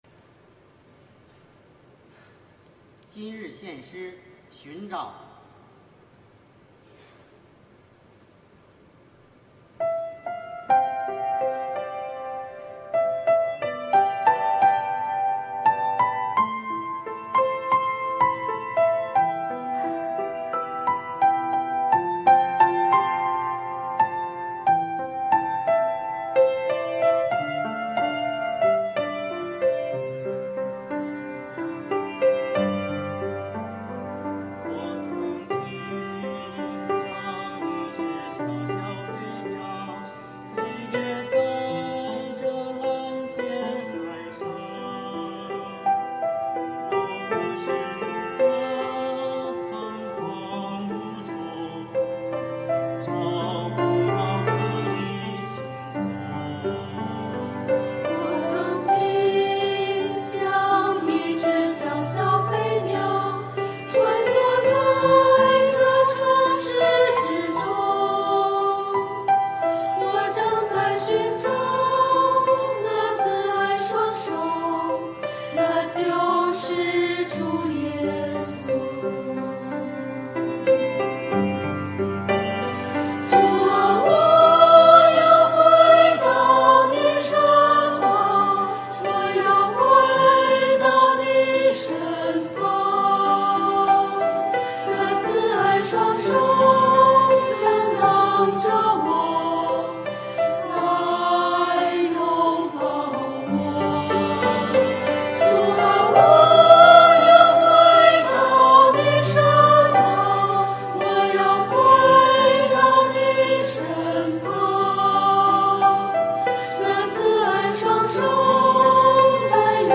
团契名称: 青年、迦密诗班
诗班献诗